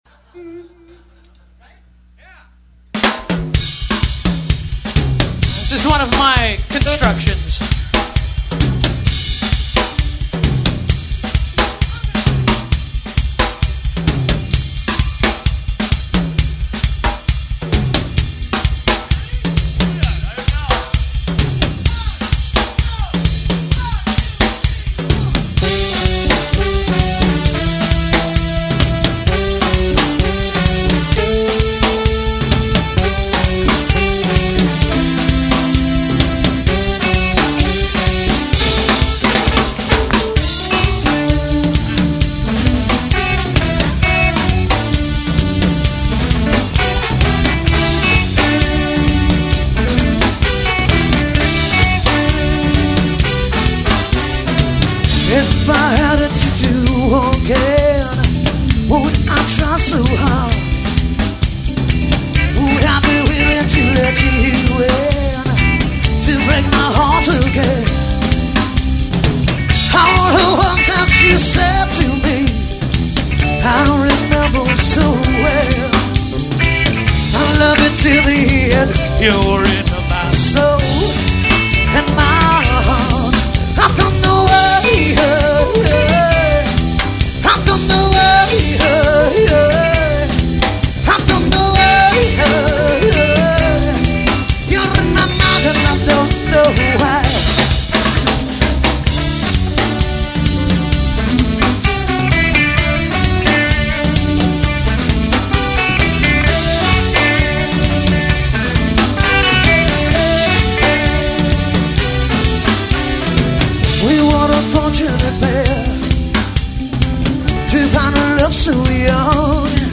Latin flavor Rock
Rock & Roll